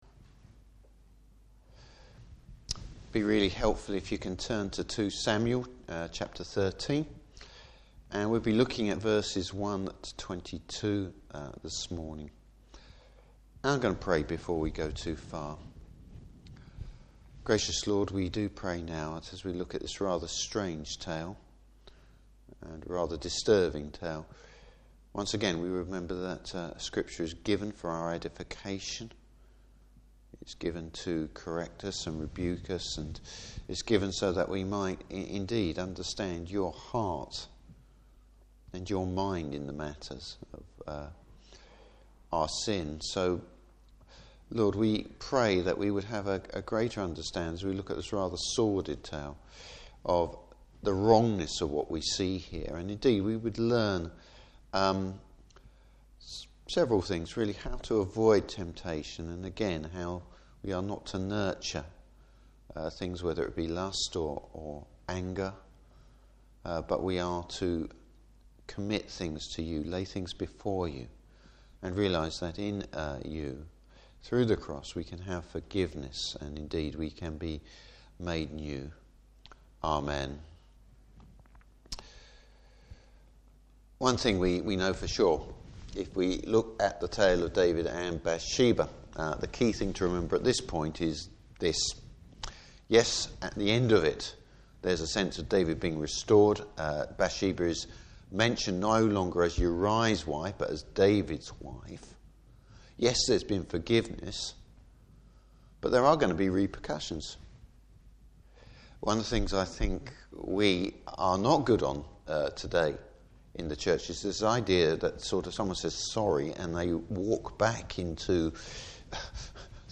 Service Type: Evangelistic Evening.